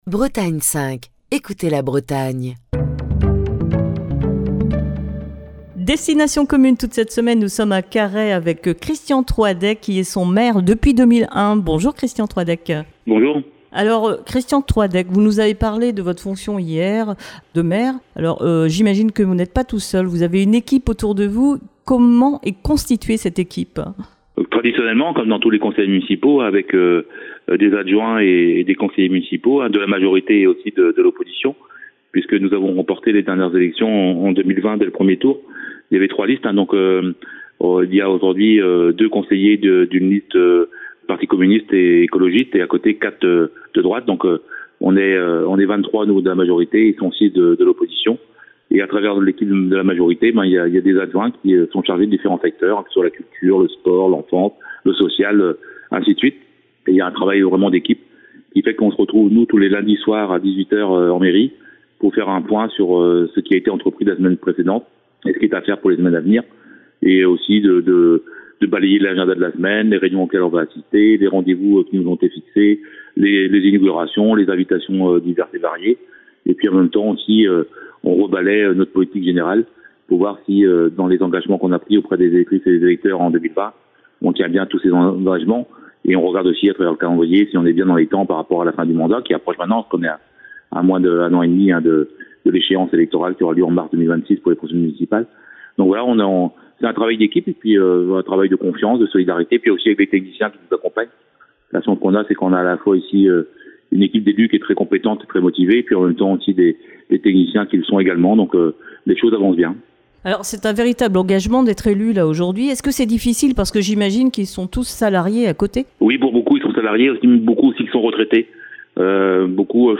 au téléphone
Christian Troadec, le maire de Carhaix